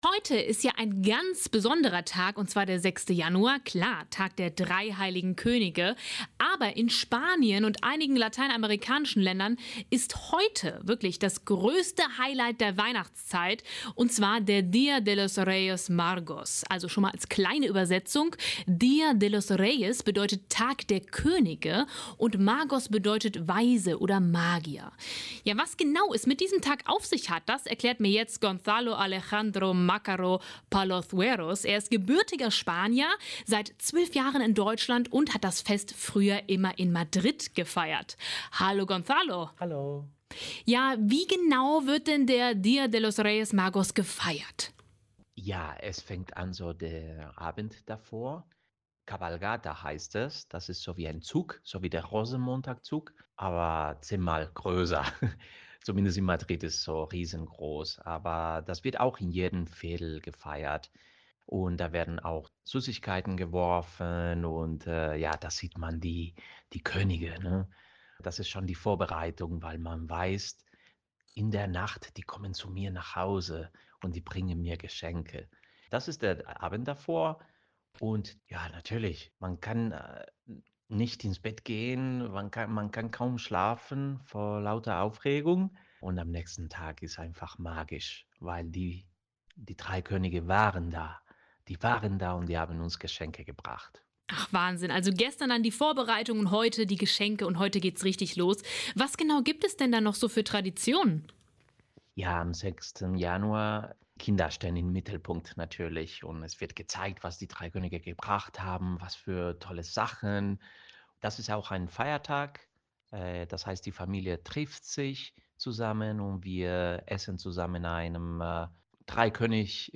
Ein Spanier erklärt, wie der Día de los Reyes Magos gefeiert wird